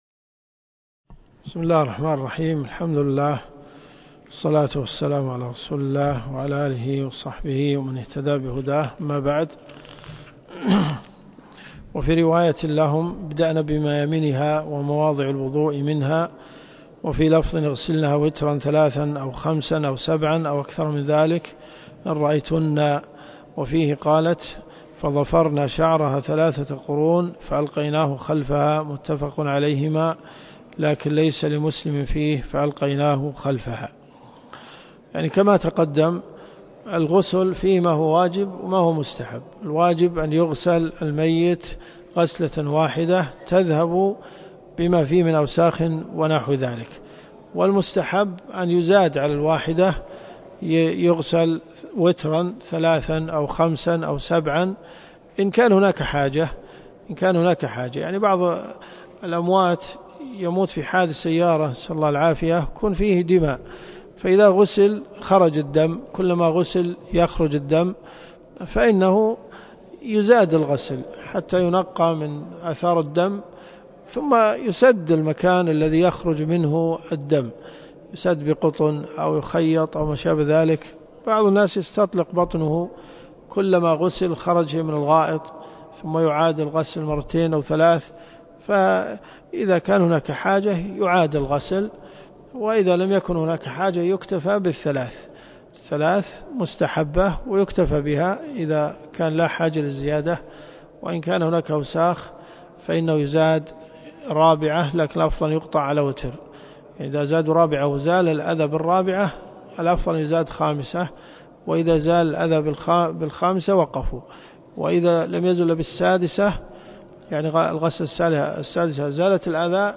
2 - 1433.3 . المنتقى من اخبار المصطفى . كتاب الجنائز . من حديث 1791 -إلى- حديث 1809 . الرياض . حي أم الحمام . جامع الملك خالد